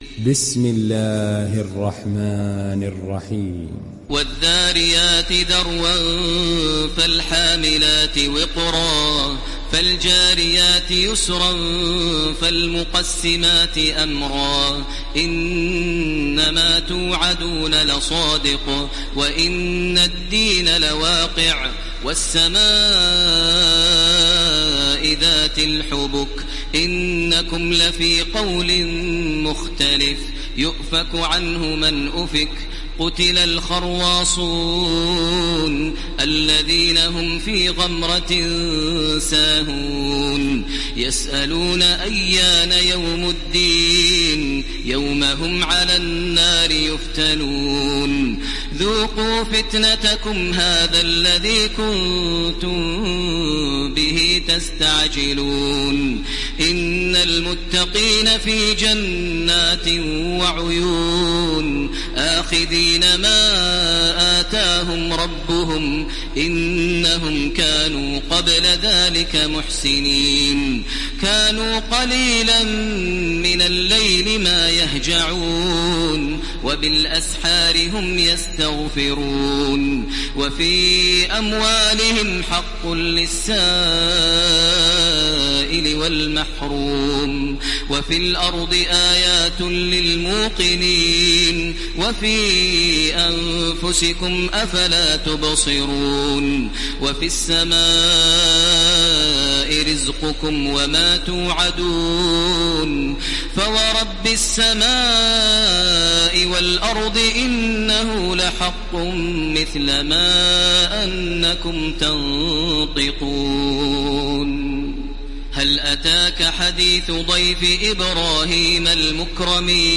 İndir Zariyat Suresi Taraweeh Makkah 1430